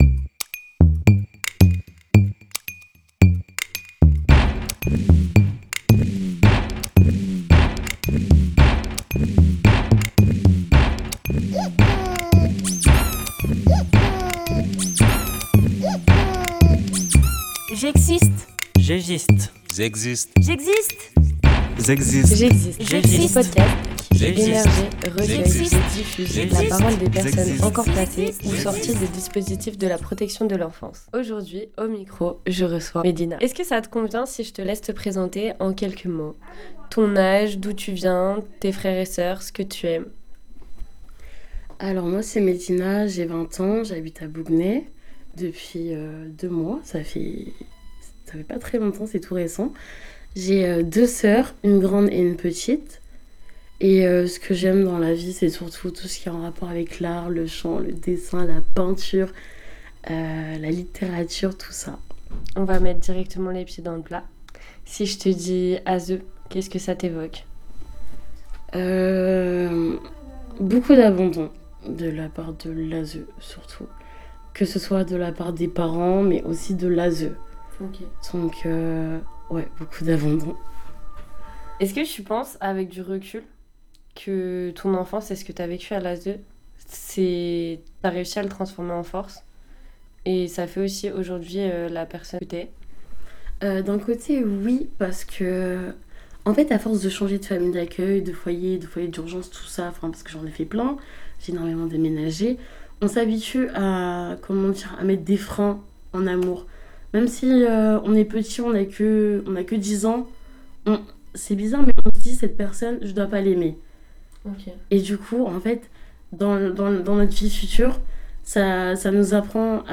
Jingle réalisé par les adhérents de l’ADEPAPE Repairs ! 44